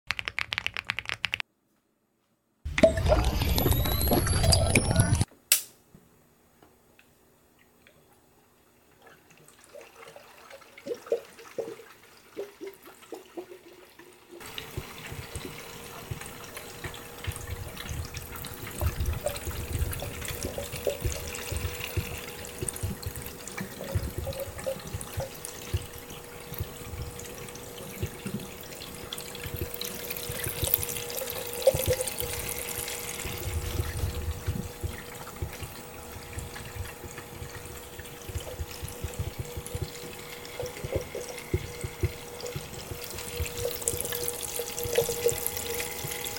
ASMR Adding the Coolant to sound effects free download